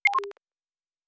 Sci-Fi Sounds / Interface / Data 04.wav